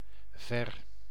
Ääntäminen
IPA: /vɛr/